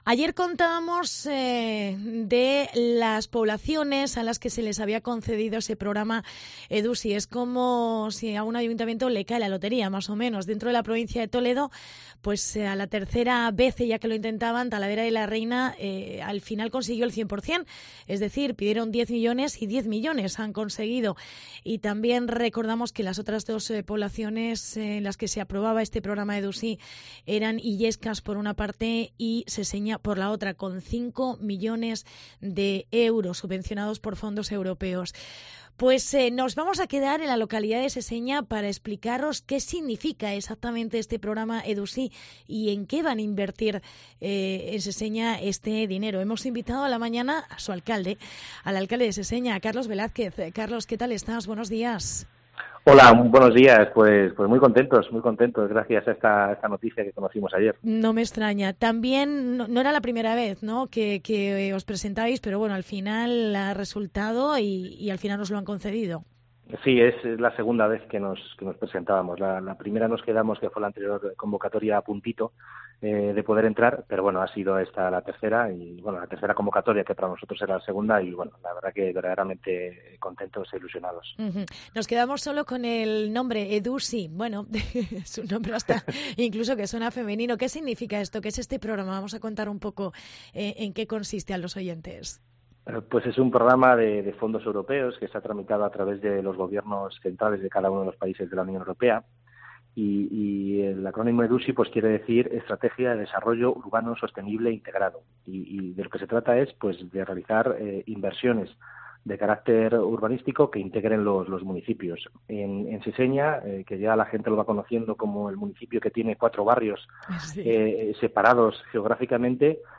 Entrevista con el alcalde: Carlos Velázquez